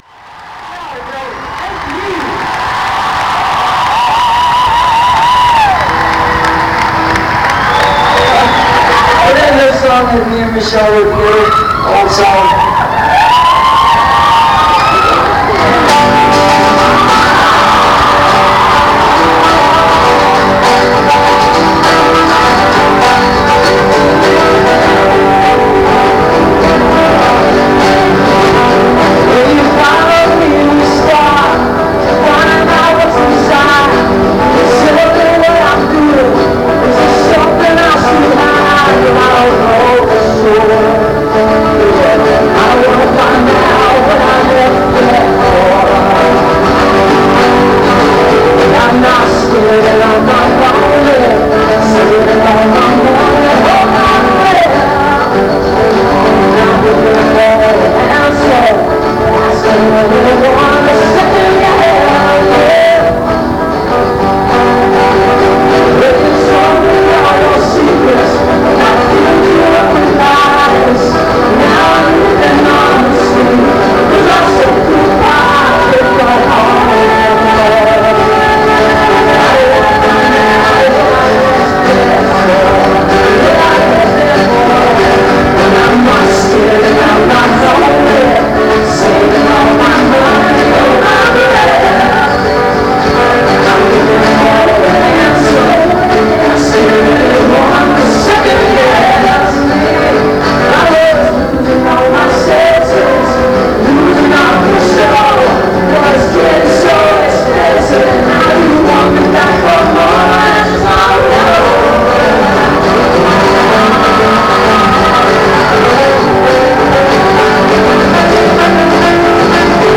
(this recording has some distortion)